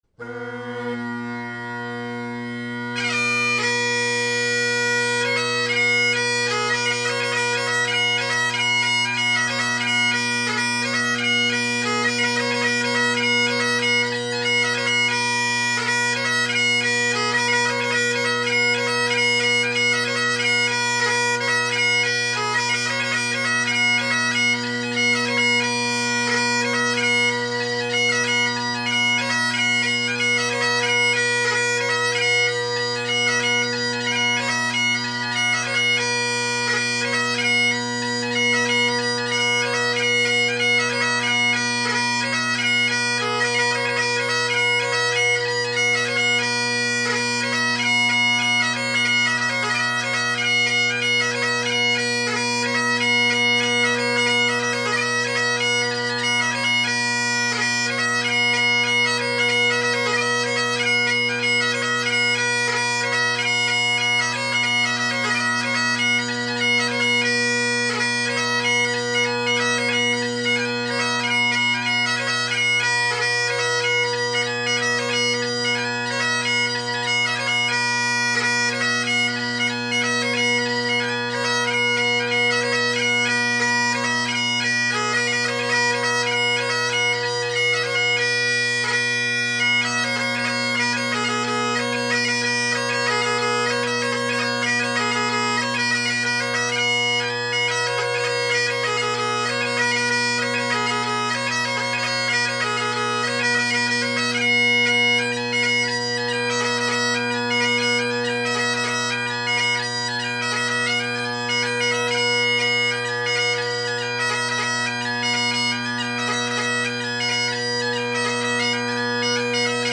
Chris Terry drones with Colin Kyo chanter (481 Hz)
Drone Sounds of the GHB, Great Highland Bagpipe Solo
The mic caught a bit too much of the bass (when will I ever learn?).